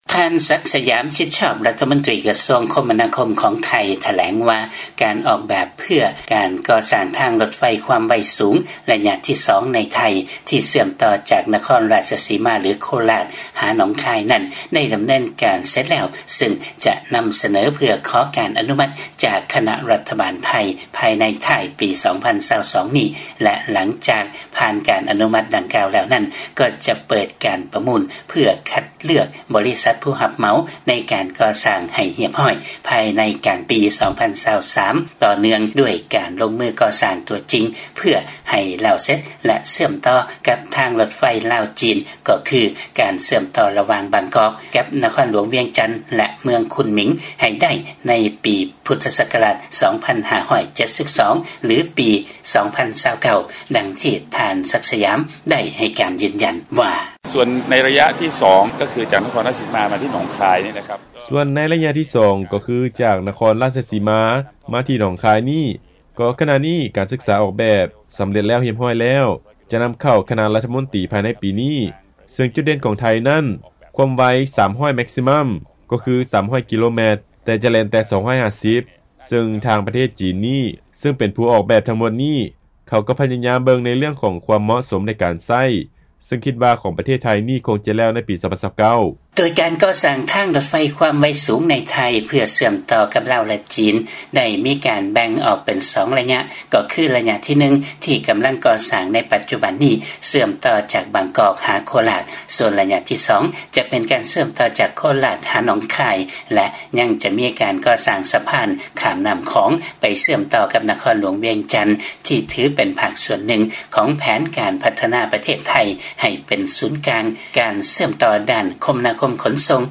ຟັງລາຍງານ ການກໍ່ສ້າງທາງລົດໄຟຄວາມໄວສູງໃນປະເທດໄທ ຈະແລ້ວສຳເລັດແລະເຊື່ອມຕໍ່ກັບທາງລົດໄຟລາວ-ຈີນຢ່າງສົມບູນນັບຈາກປີ 2029